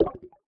select_itemcard.ogg